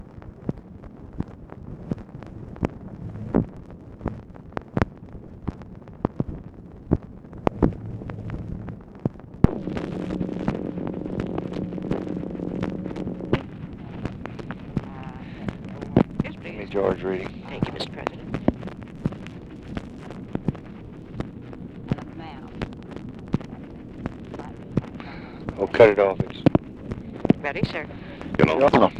LBJ ASKS TELEPHONE OPERATOR TO PLACE CALL TO GEORGE REEDY; RECORDING IS INTERRUPTED JUST AS REEDY ANSWERS TELEPHONE
Conversation with GEORGE REEDY, TELEPHONE OPERATOR and OFFICE CONVERSATION, June 15, 1965